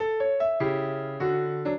piano
minuet6-4.wav